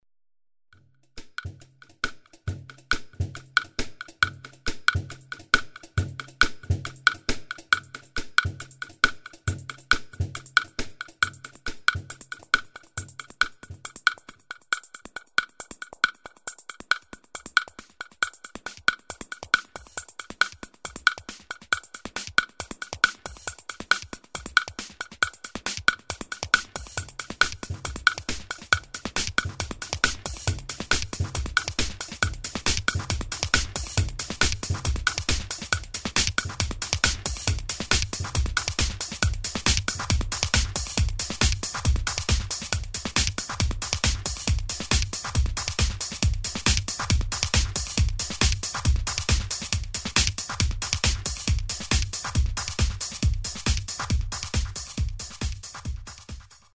Demo Dance and House Mix